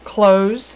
Close Sound Effect
close-1.mp3